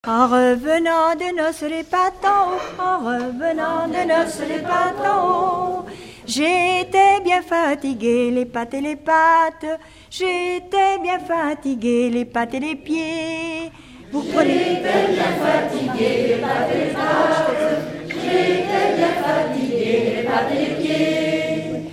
En revenant des noces Cette Pièce musicale inédite a pour titre "En revenant des noces".
chanson avec réponse